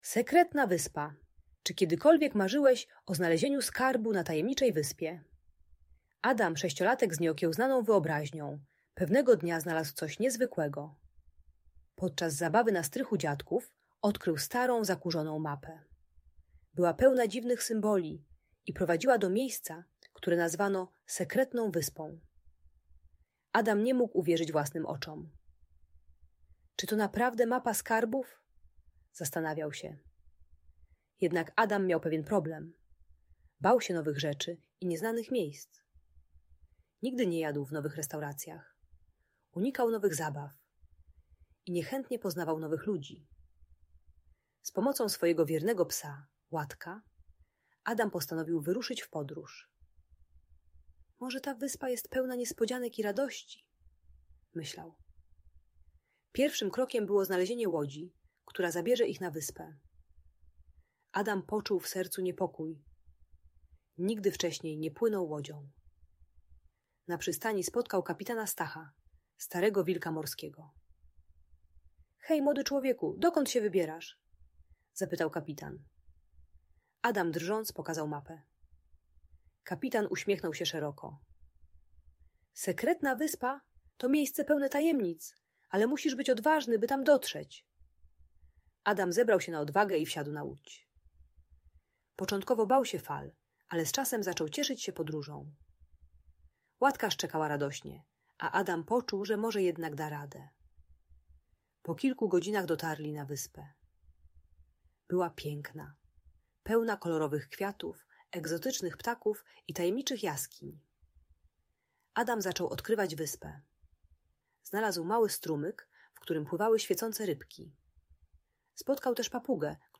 Sekretna Wyspa - Lęk wycofanie | Audiobajka